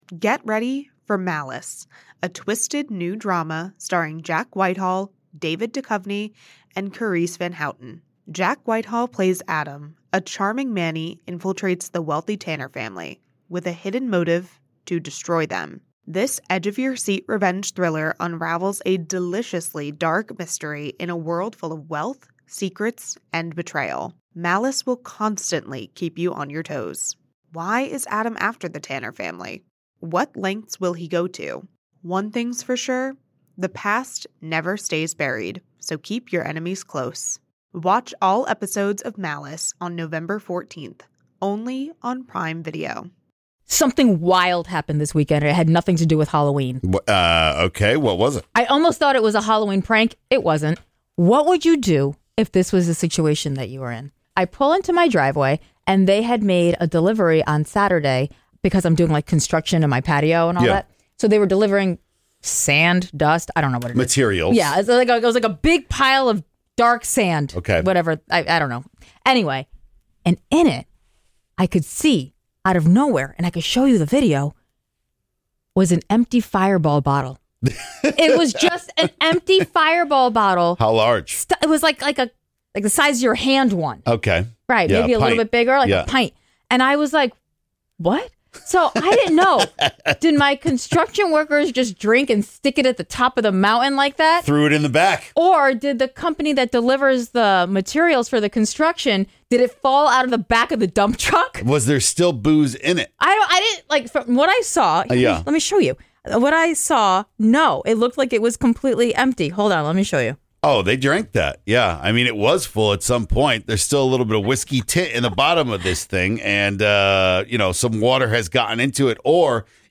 Callers talk about what happened on their holiday night!
And callers also share sounds that make their pup go nuts!